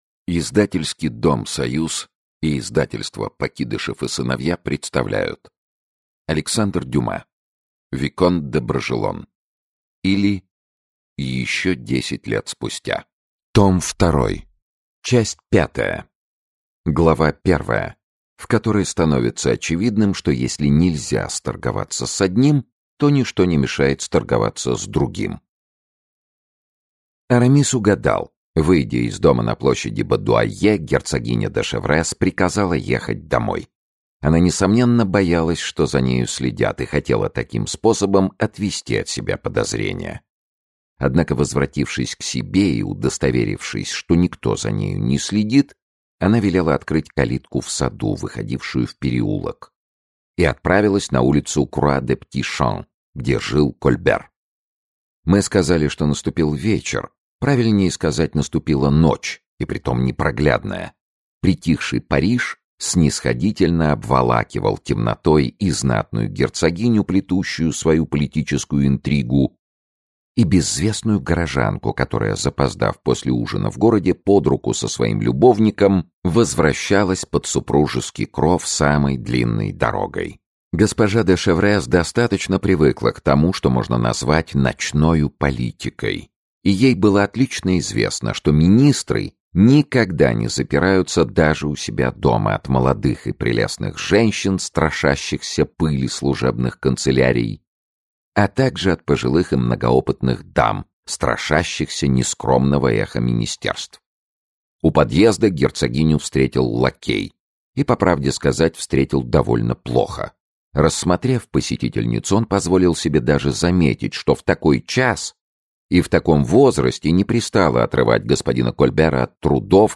Aудиокнига Виконт де Бражелон.
Часть V Автор Александр Дюма Читает аудиокнигу Сергей Чонишвили.